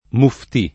vai all'elenco alfabetico delle voci ingrandisci il carattere 100% rimpicciolisci il carattere stampa invia tramite posta elettronica codividi su Facebook muftī [ar. m 2 ftii ] s. m. — italianizz. in muftì [ muft &+ ] (meno com. mufti [ m 2 fti ])